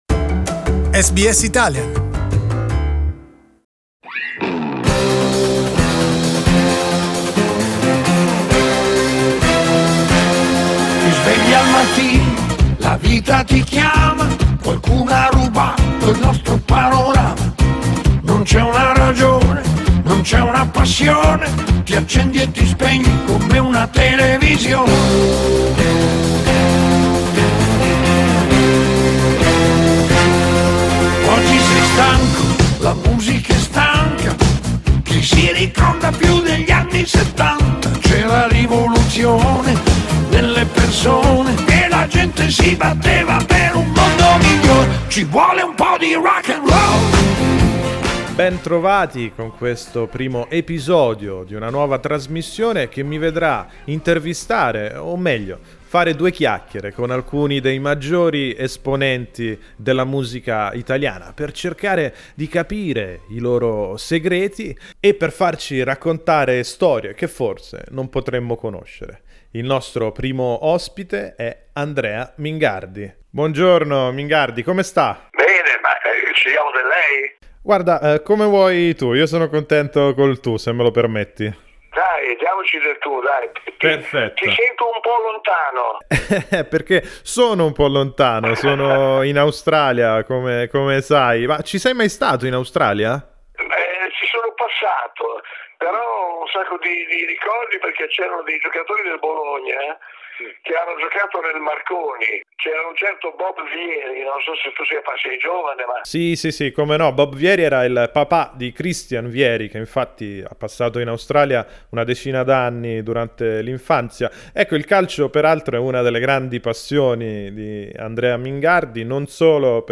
Parlando di musica - quattro chiacchiere con Andrea Mingardi, da Mina alla nazionale cantanti.